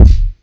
GooseBumps Kick.wav